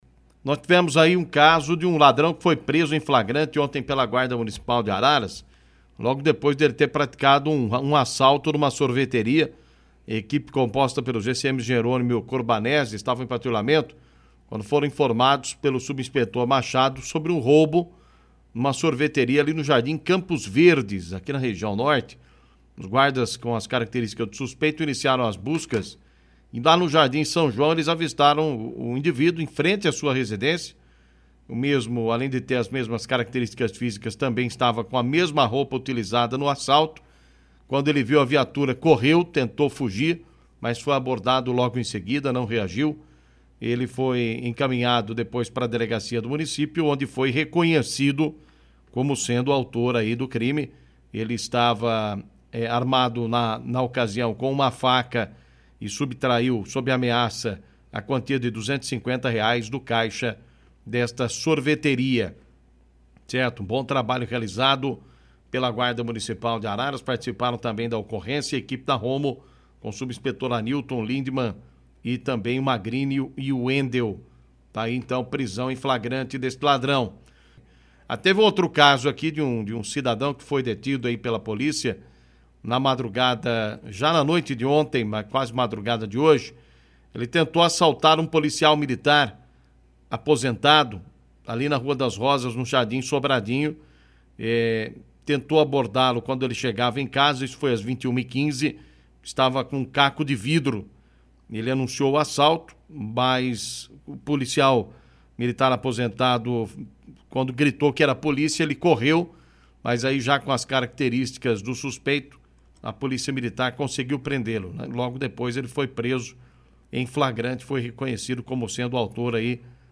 Rádio Clube • 101,7 FM 🔴 AO VIVO